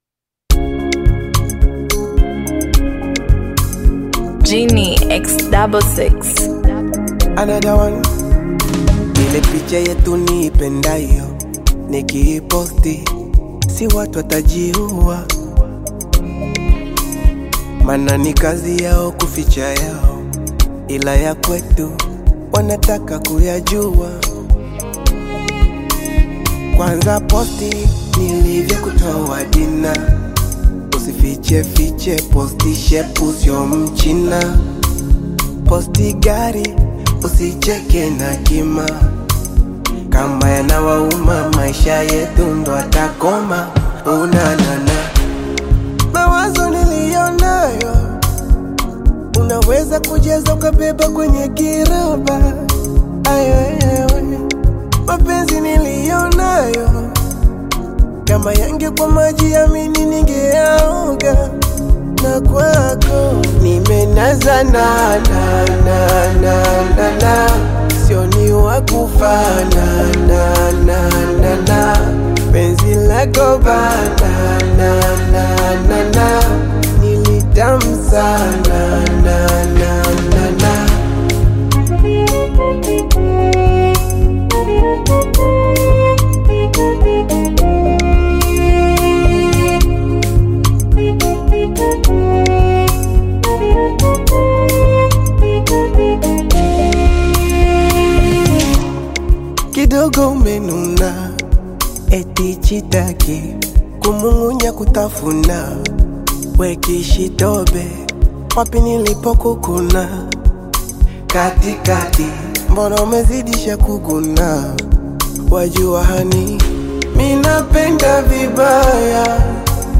heartfelt Afro-fusion single
blending warm melodies with sincere lyrics that honor love
With expressive vocal delivery and polished production
Genre: Bongo Flava